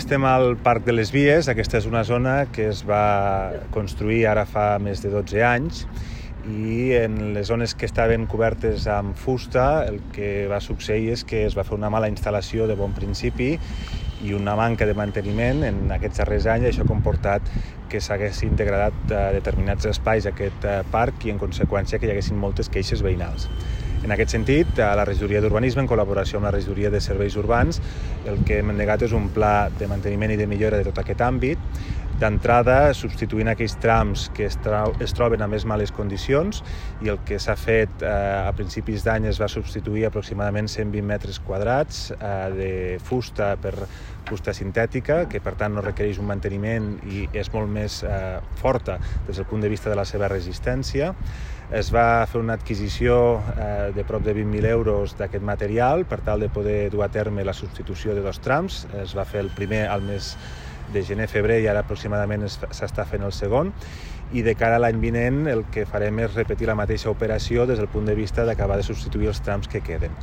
tall-de-veu-del-primer-tinent-dalcalde-toni-postius